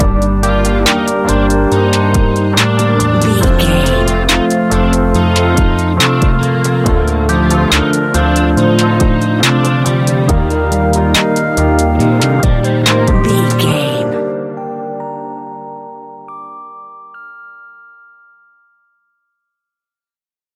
Ionian/Major
A♯
chilled
laid back
Lounge
sparse
new age
chilled electronica
ambient
atmospheric
morphing